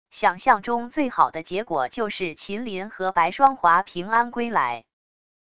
想象中最好的结果就是秦林和白霜华平安归来wav音频生成系统WAV Audio Player